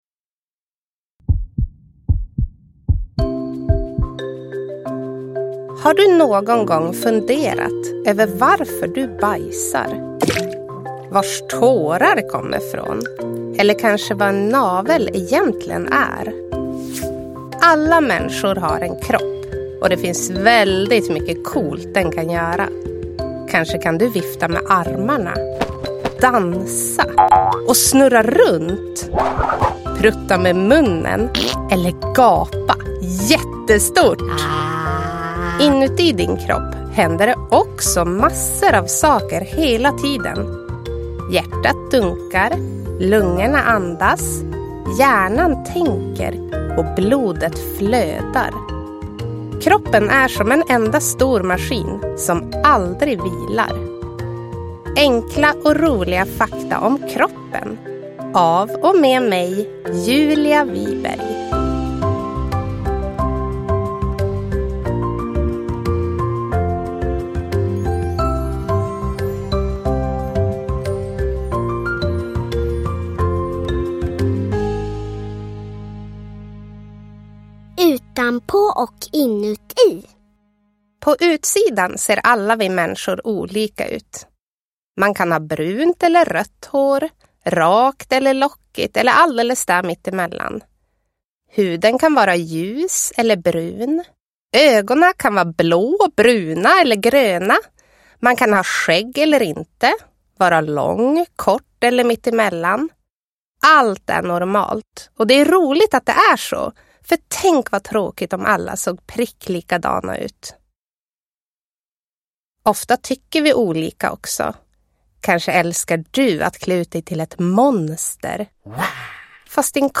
Enkla och roliga fakta om kroppen – Ljudbok
Berättar gör Julia Wiberg, barnens favorit från Djur med Julia på SVT Barn.
Uppläsare: Julia Wiberg